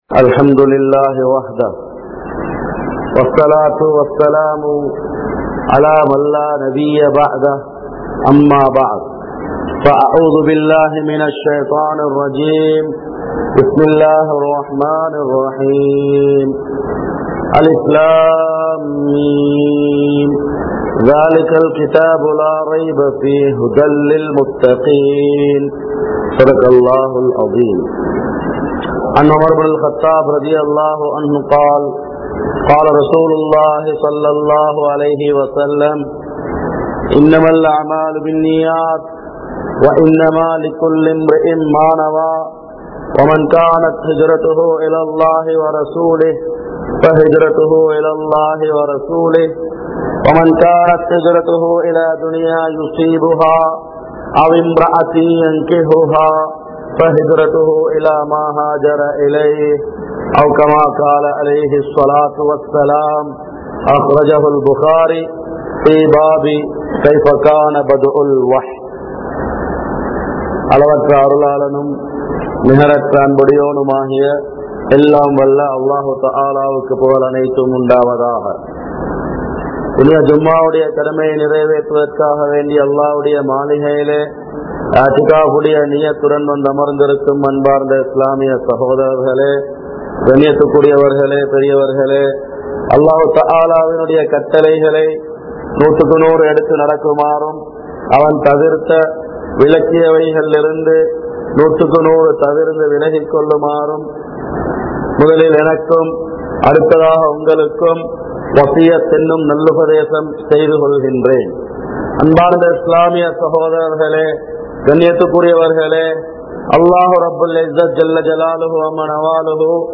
Poruppuhalai Niraiveattra Thavarinaal (பொறுப்புகளை நிறைவேற்ற தவறினால்) | Audio Bayans | All Ceylon Muslim Youth Community | Addalaichenai